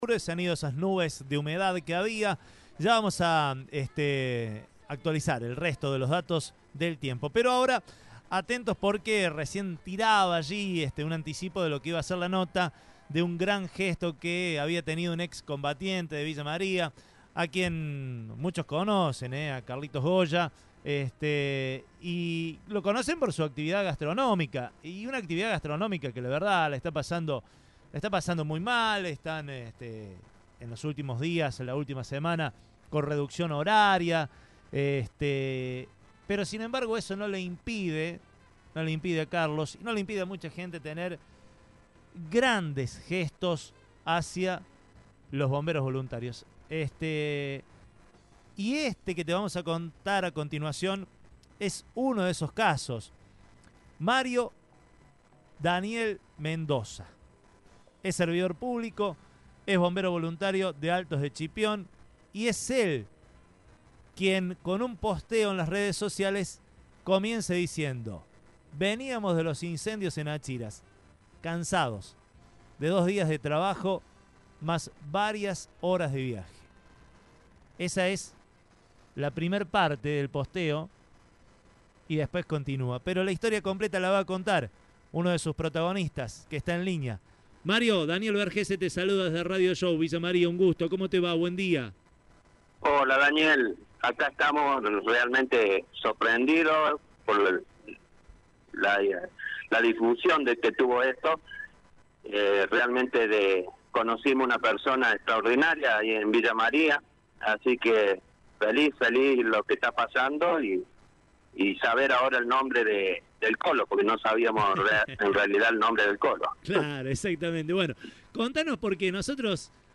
en diálogo con Radio Show